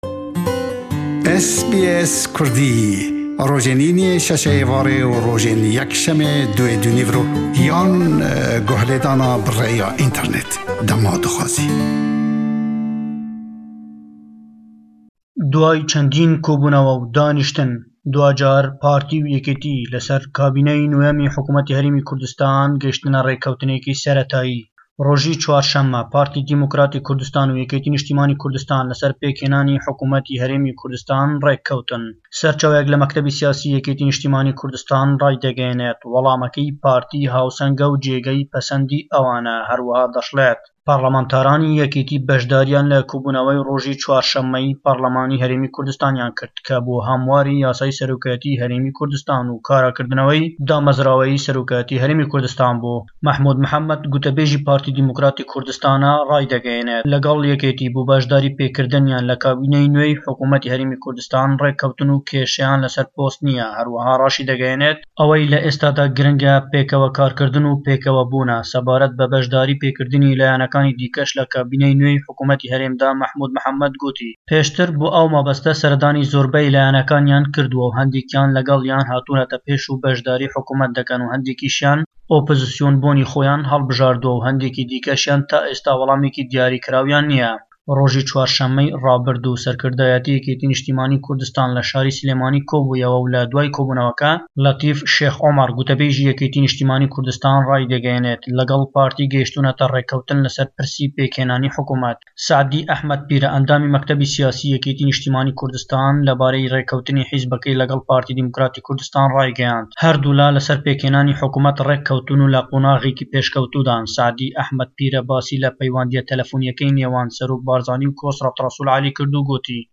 le Hewlêre we rûmallî pêşkewtinekan dekat sebaret be rêkewtinî YNK û PDK bo pêkhênanî kabîney nwêy hukûmetî Herêmî Kurdistan.